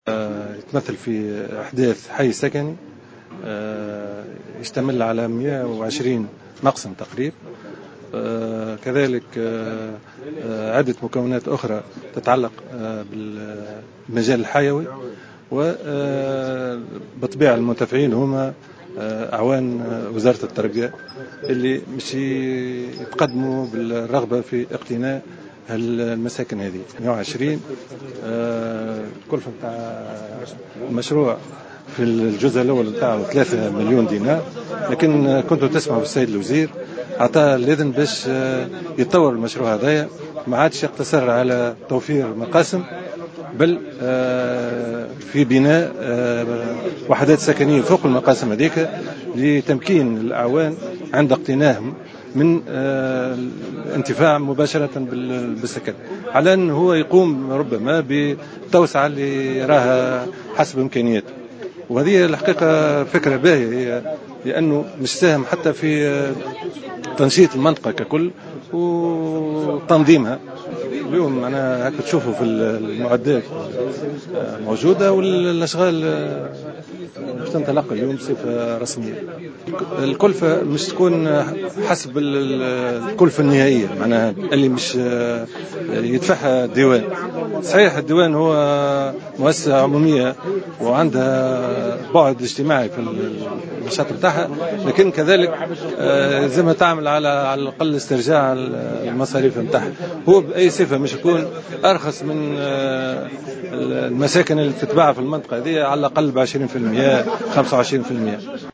تصريح لمراسل "الجوهرة أف أم" اليوم على هامش إعطاء إشارة انطلاق بناء الحي السكني التربوي بالمنيهلة